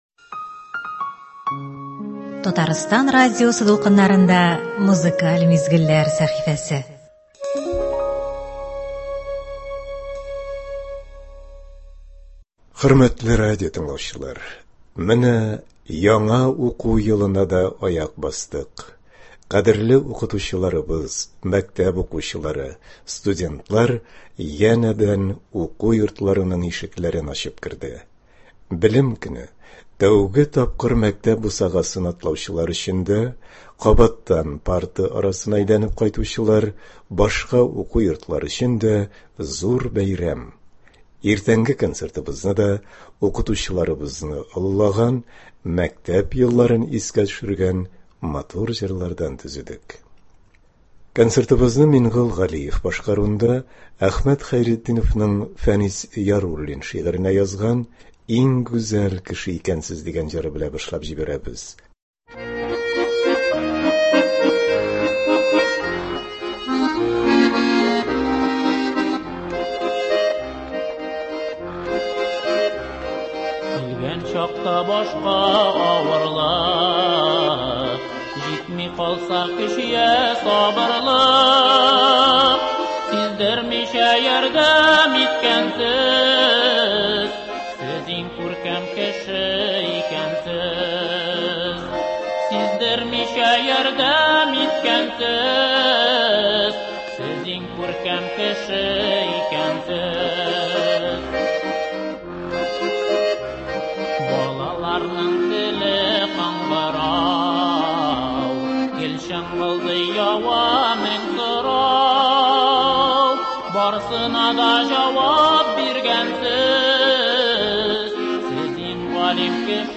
Белем көненә багышланган концерт.